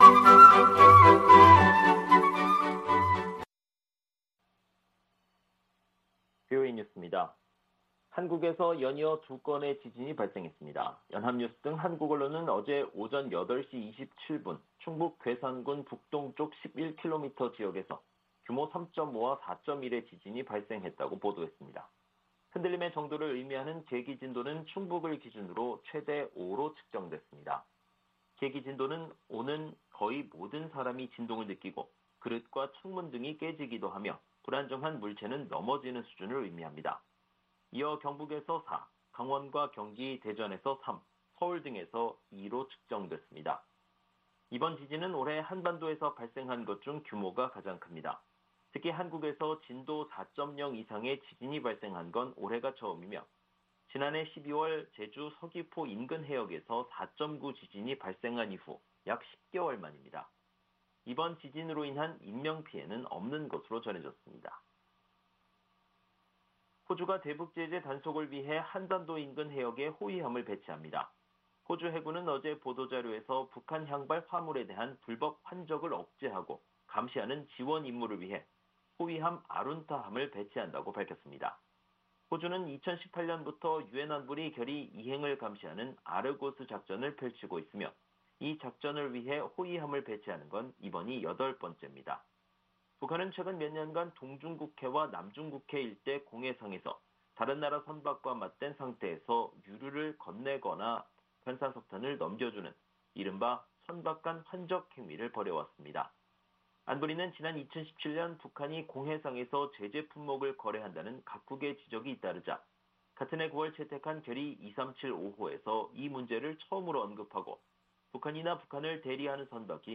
VOA 한국어 방송의 토요일 오후 프로그램 3부입니다.